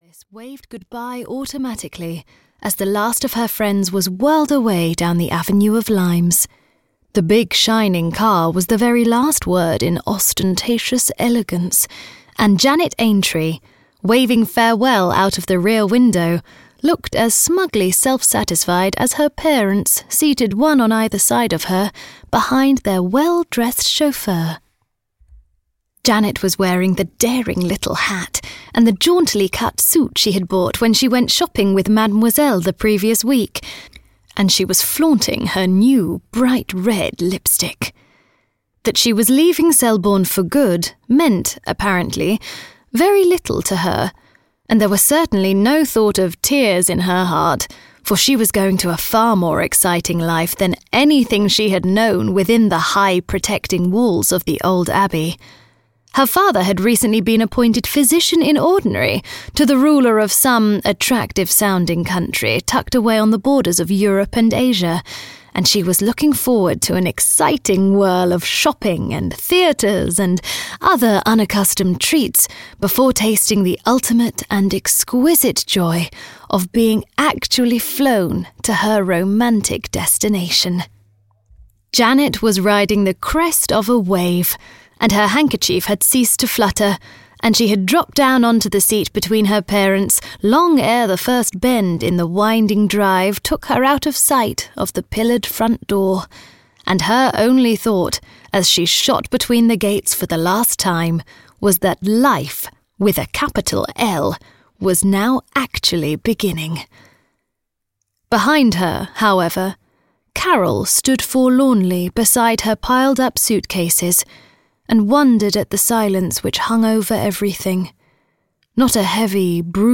Mistress of Brown Furrows (EN) audiokniha
Ukázka z knihy